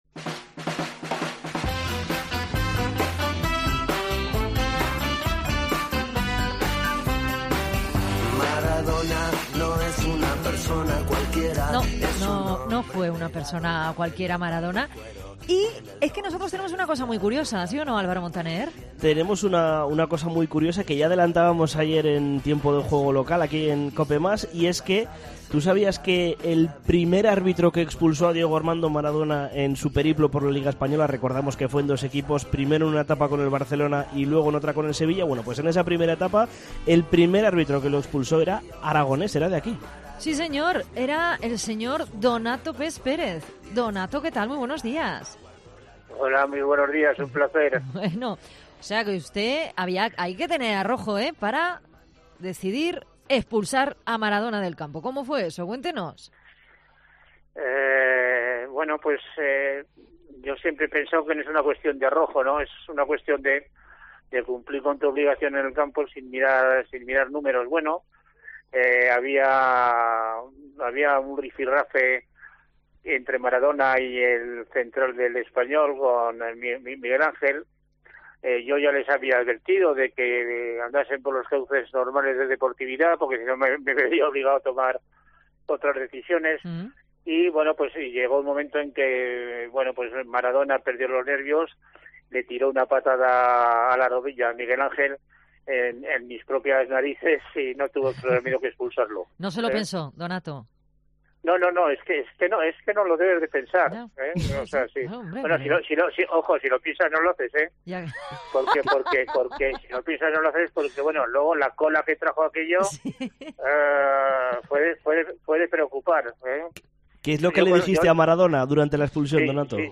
Deportes en Zaragoza Entrevista